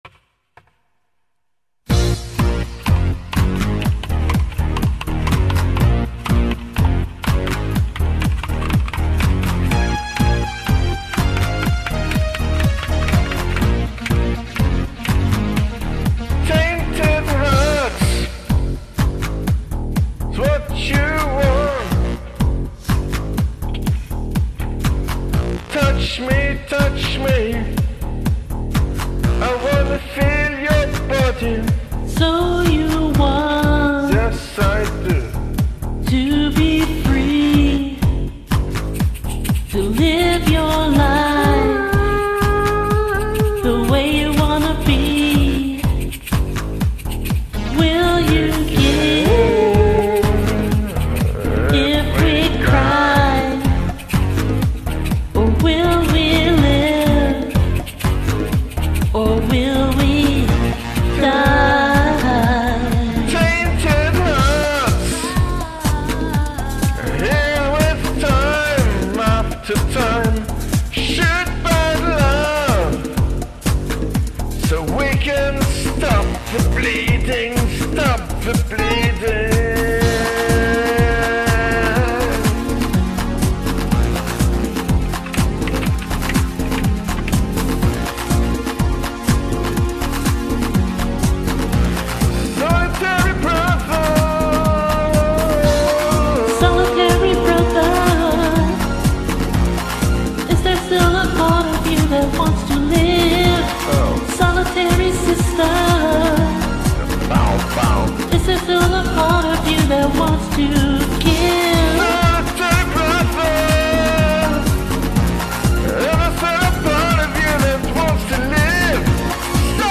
Karaoke Covers (Pop)